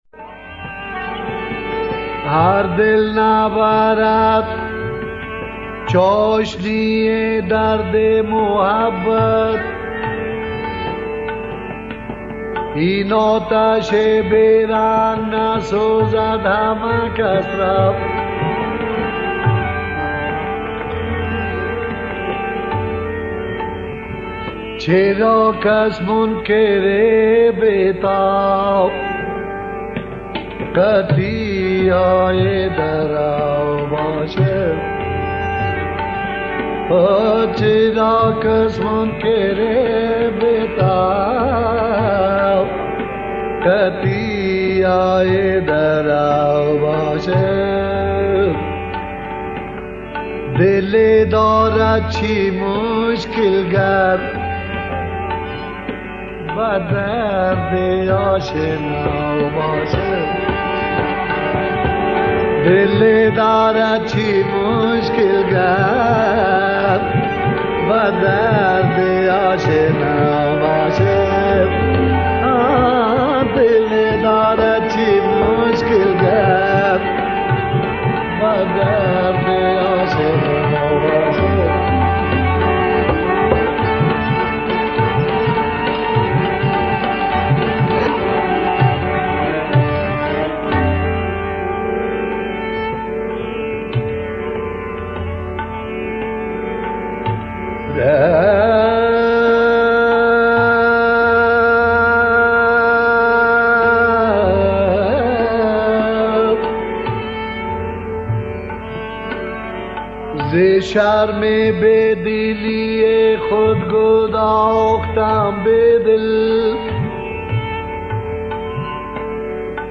دو اجرا با دو ملودی از یک شعر بیدل.